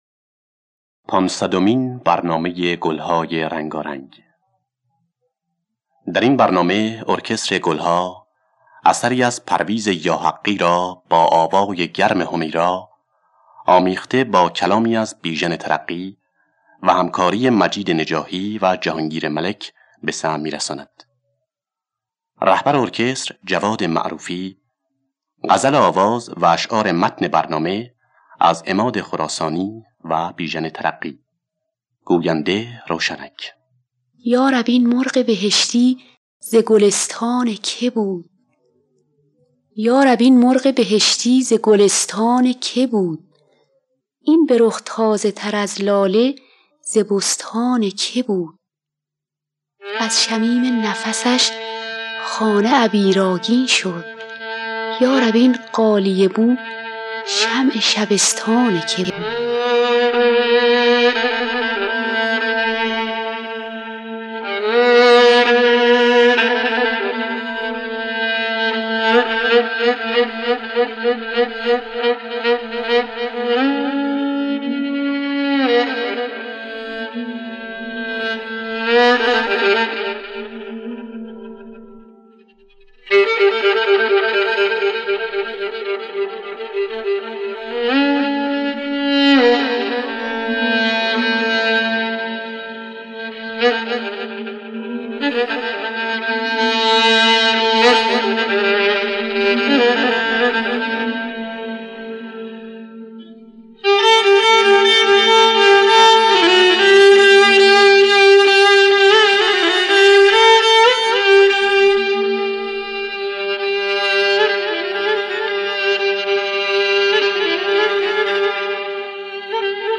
خوانندگان: حمیرا نوازندگان